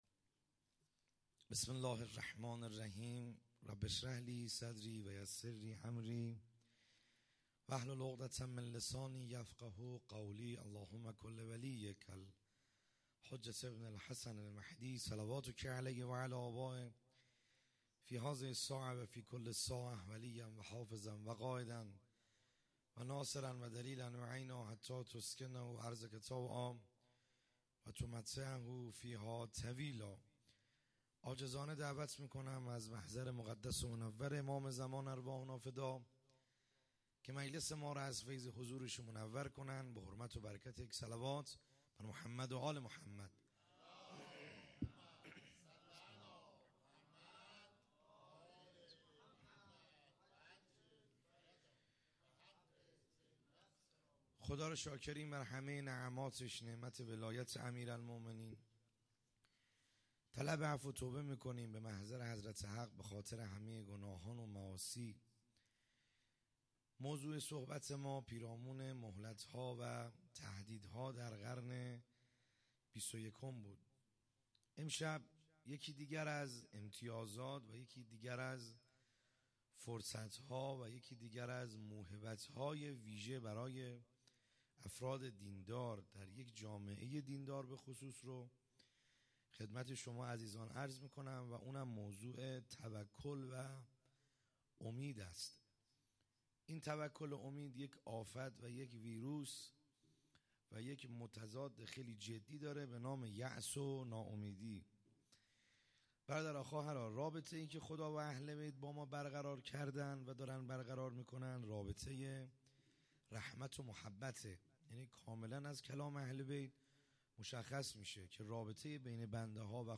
خیمه گاه - بیرق معظم محبین حضرت صاحب الزمان(عج) - سخنرانی | شب بیست و چهارم